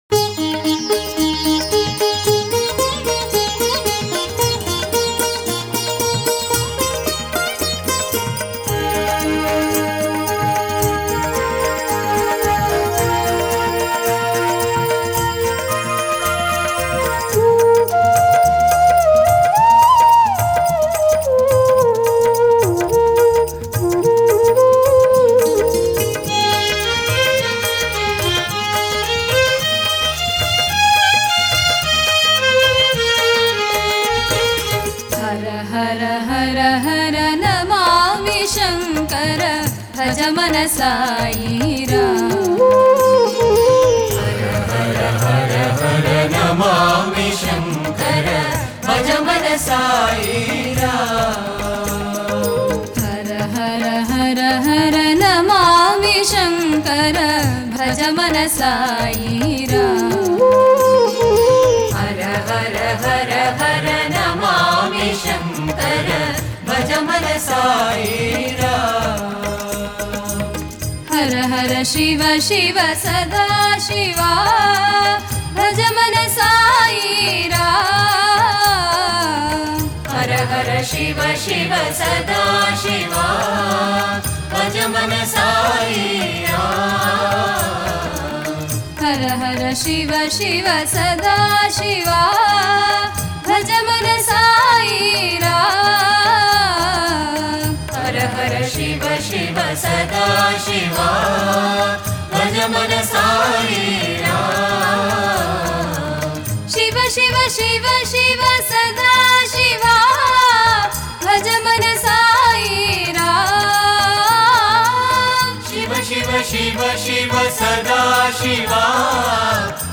Author adminPosted on Categories Shiva Bhajans